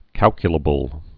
(kălkyə-lə-bəl)